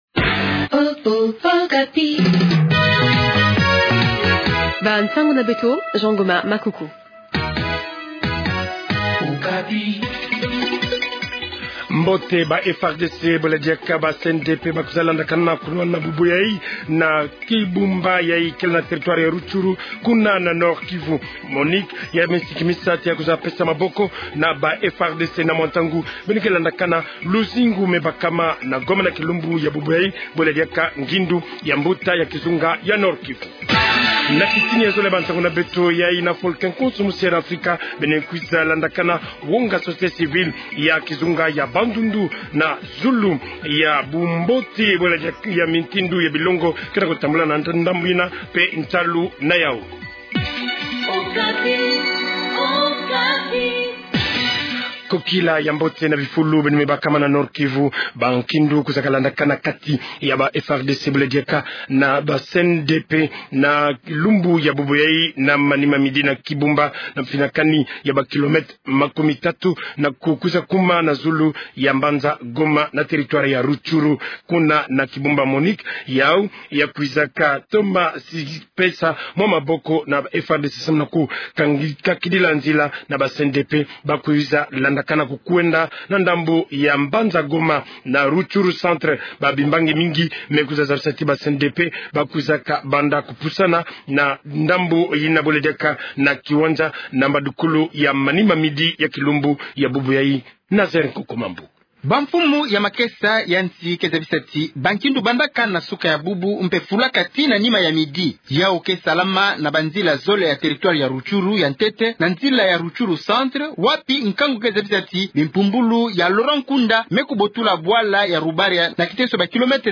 Journal Kikongo Soir